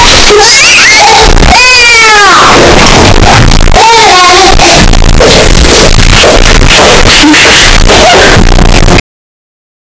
A 10-second sound effect of a one-year-old child playing inside a room. The child is constantly moving: unstable footsteps while walking, then running excitedly, going up and down stairs multiple times. Includes baby laughter, playful giggles, and unintelligible baby babbling sounds. Clear footstep and stair sounds, with subtle indoor room ambience in the background. Overall feeling: high energy, playful, innocent, natural, and spontaneous. No music, sound effects only, realistic audio. 0:10 people walking,/moving around 0:10 una persona abre una puerta y se oyen pasos hasta que deja las llaves encima de la mesa 0:20